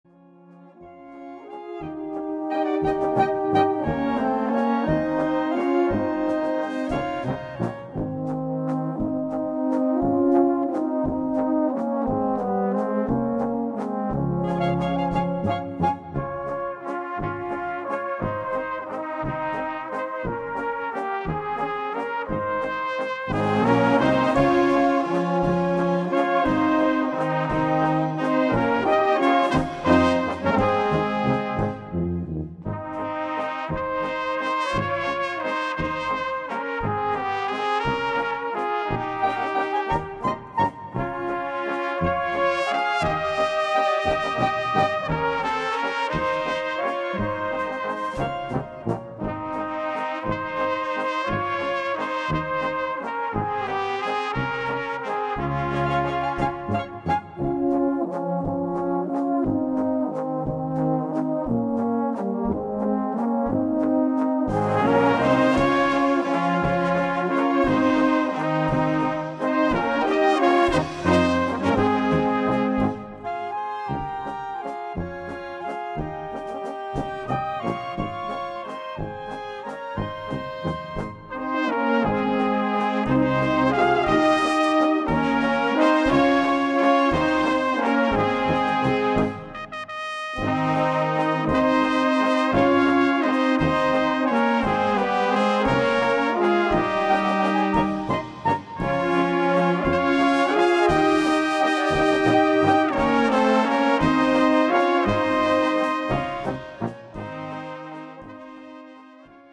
Gattung: Walzer
Besetzung: Blasorchester